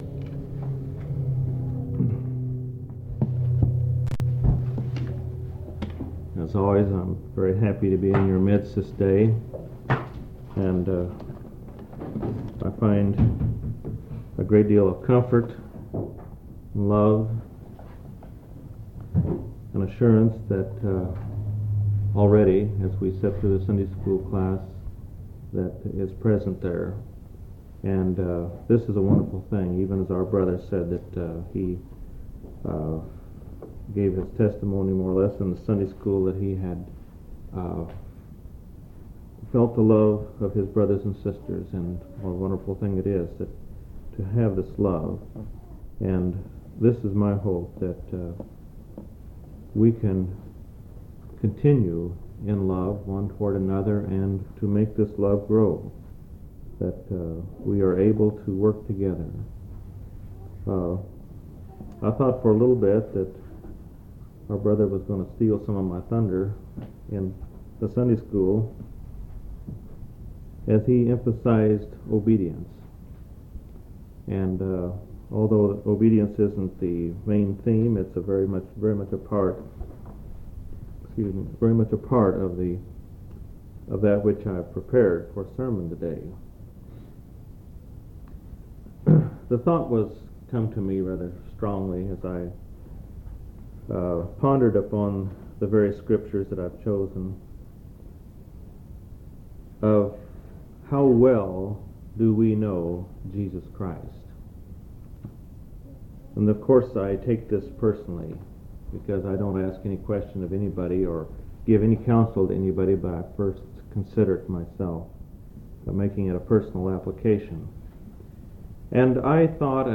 11/14/1982 Location: Houston Local Event